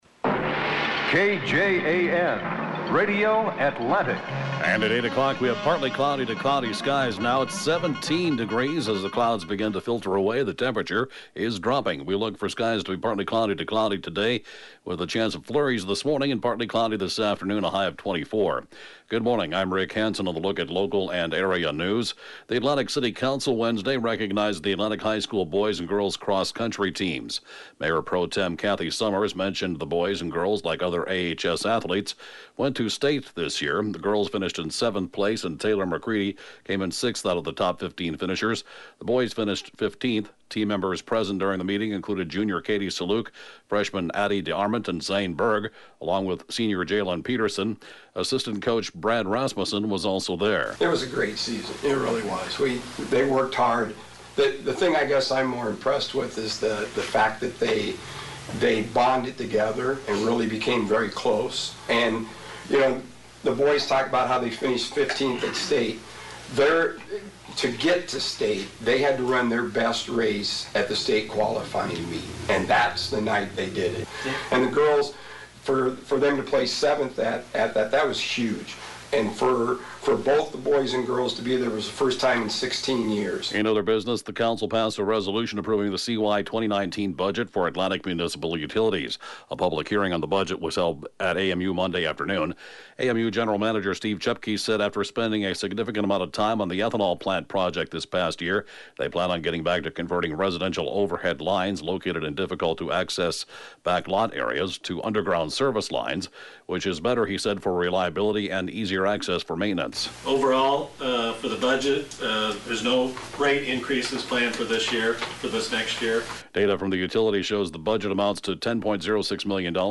KJAN News can be heard at five minutes after every hour right after Fox News 24 hours a day!